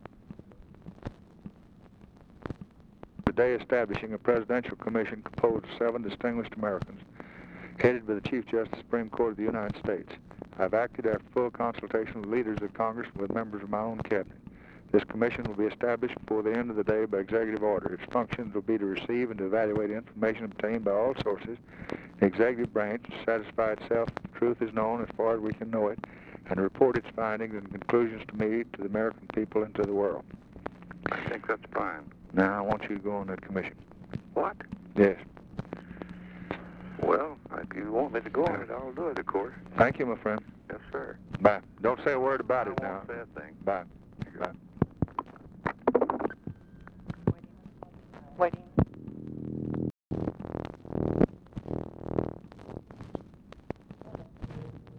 Conversation with JOHN SHERMAN COOPER, November 29, 1963
Secret White House Tapes